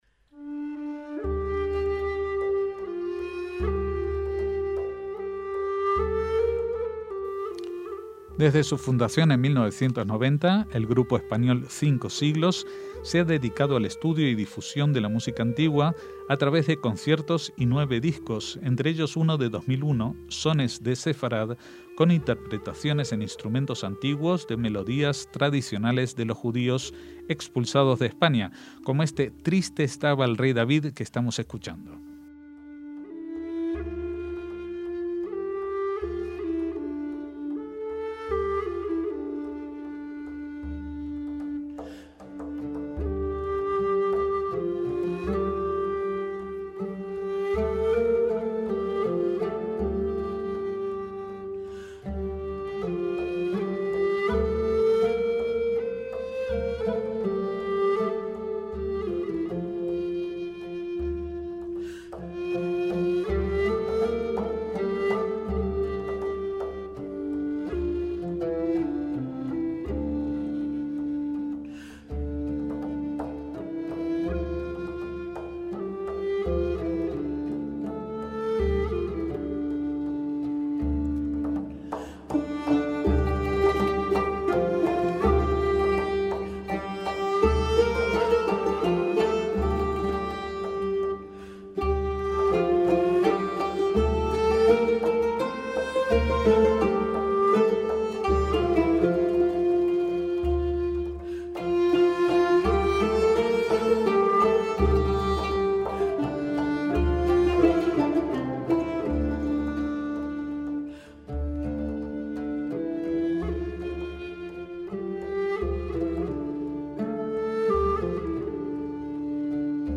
MÚSICA SEFARDÍ